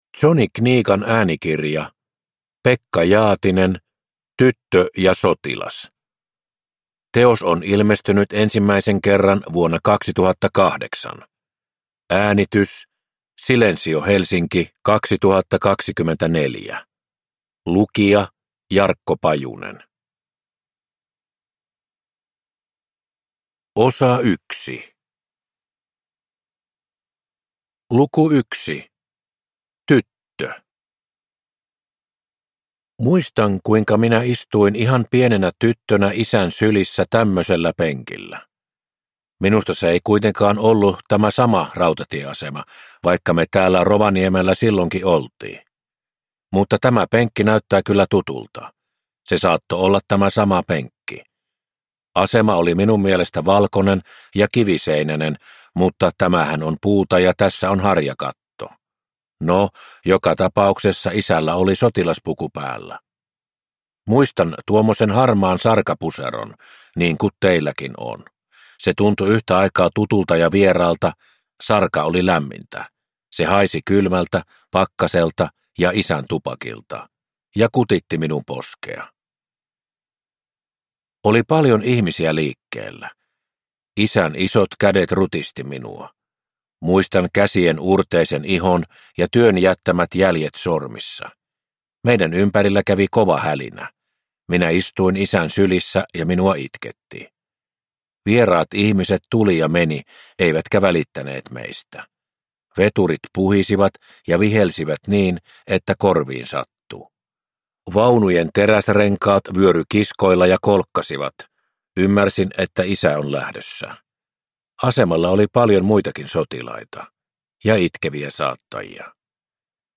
Tyttö ja sotilas – Ljudbok